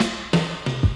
Chopped Fill 12.wav